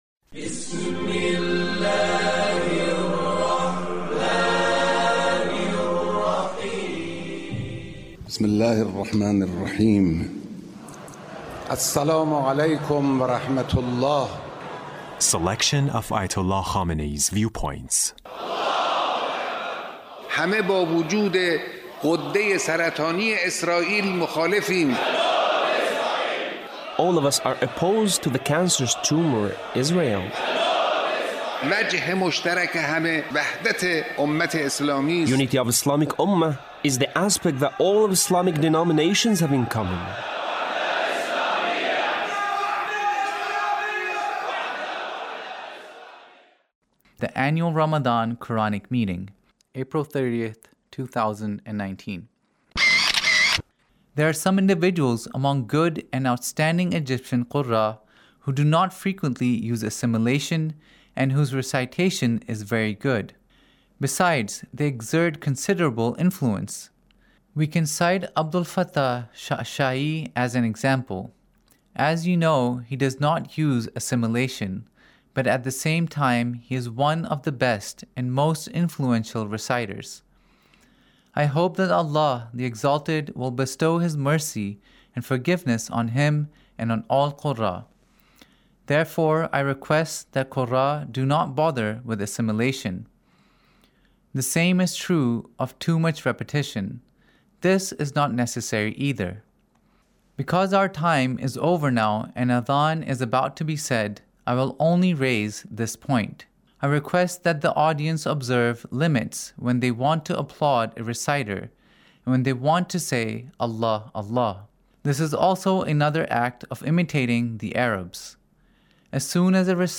Leader's Speech Of The Quranic Reciters